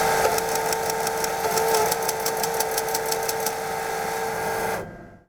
eat-duh-cashnomnom.wav